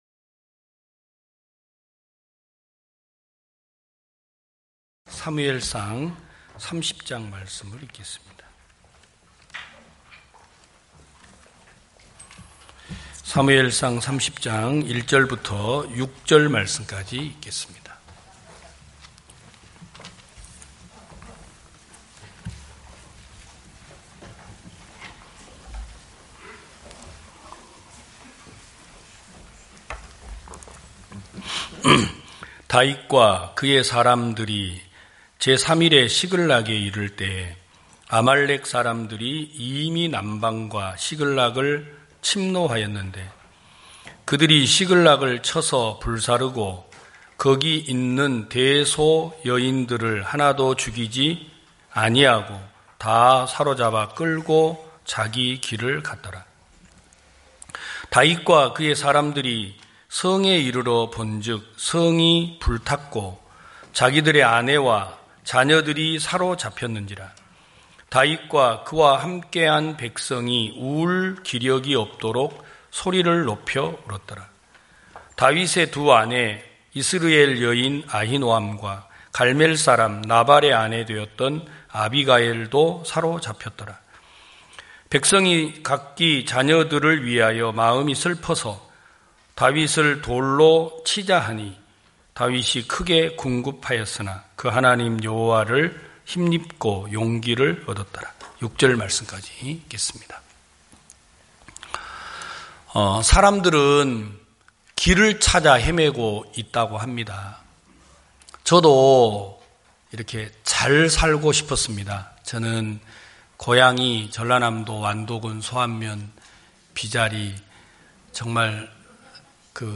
2022년 05월 08일 기쁜소식부산대연교회 주일오전예배
성도들이 모두 교회에 모여 말씀을 듣는 주일 예배의 설교는, 한 주간 우리 마음을 채웠던 생각을 내려두고 하나님의 말씀으로 가득 채우는 시간입니다.